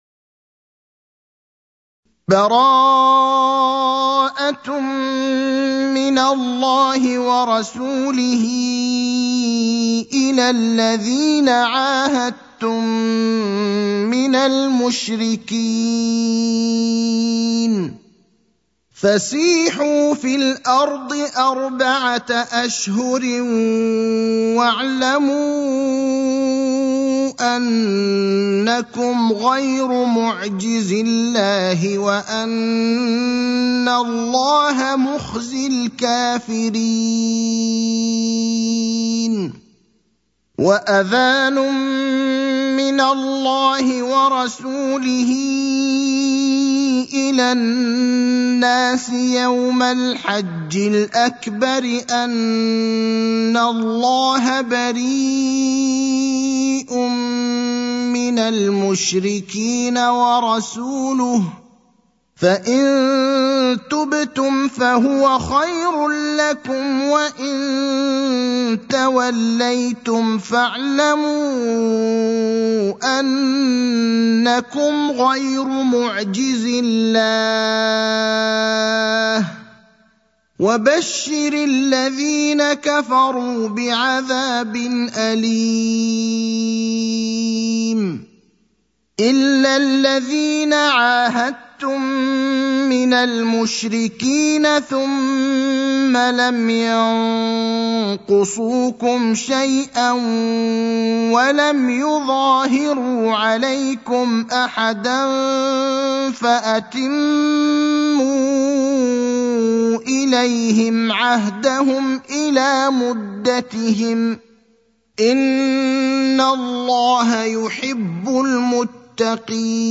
المكان: المسجد النبوي الشيخ: فضيلة الشيخ إبراهيم الأخضر فضيلة الشيخ إبراهيم الأخضر التوبة (9)